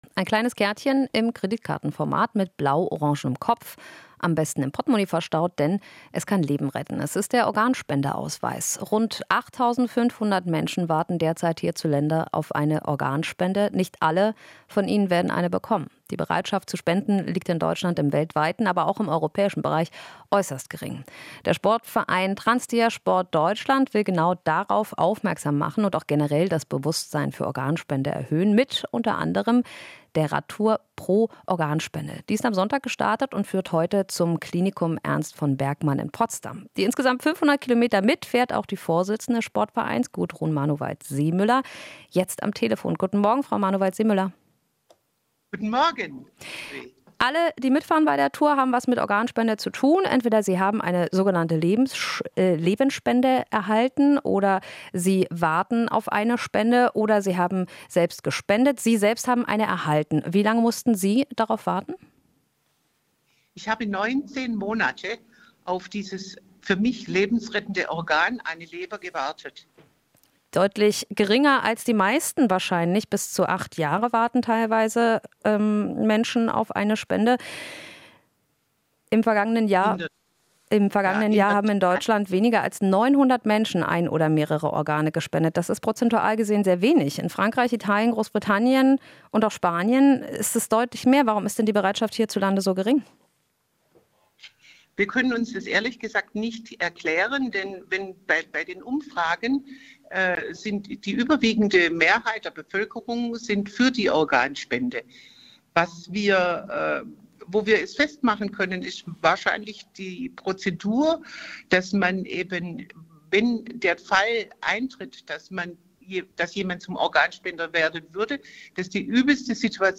Interview - Verein: Wahrscheinlicher, ein Organ zu brauchen, als Spender zu werden